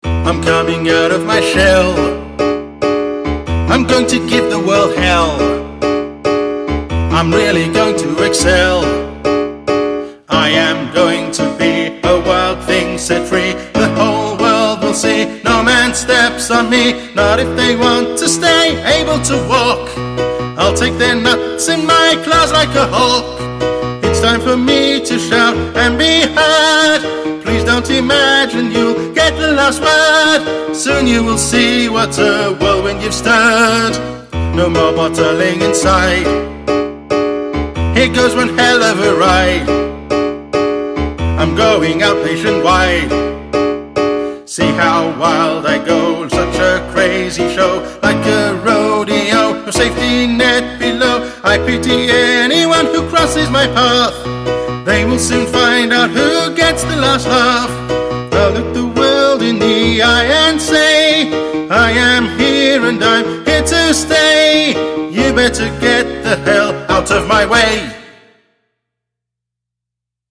Alternative Acoustic